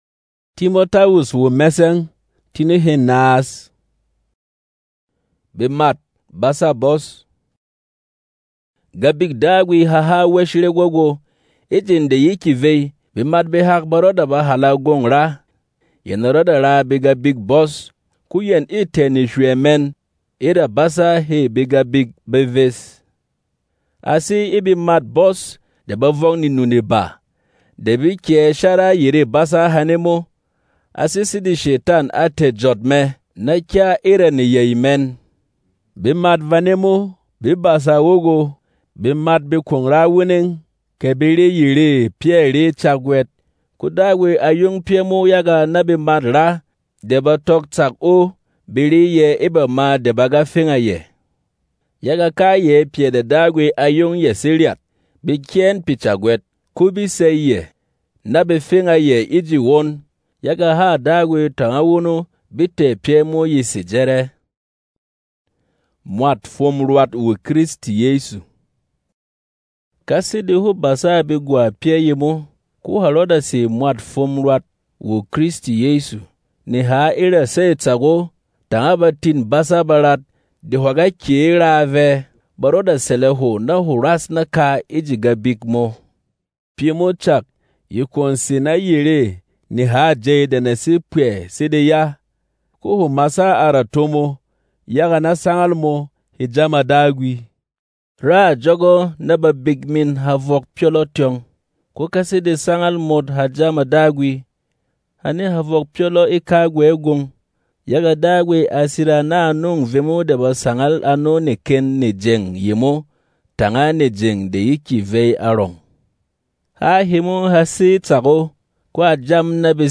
Berom MP3 Bible ⚙
Non-Drama